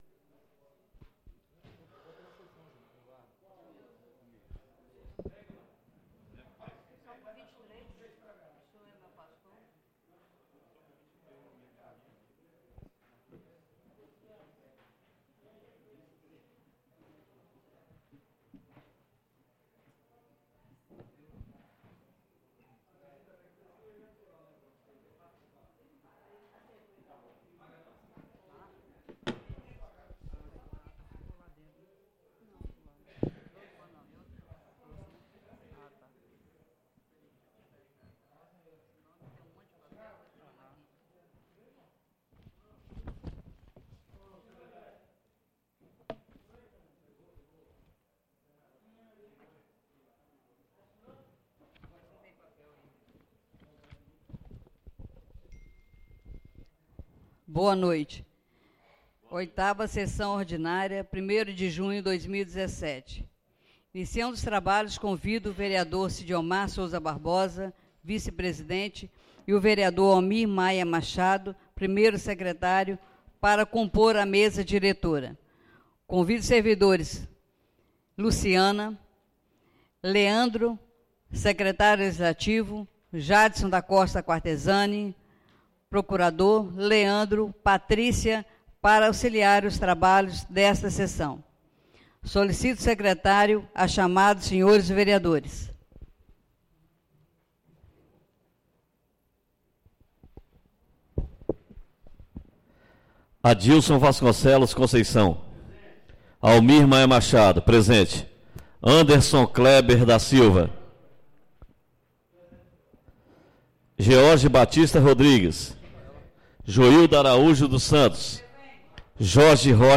8ª (OITAVA) SESSÃO ORDINÁRIA DO DIA 1º DE JUNHO DE 2017 SEDE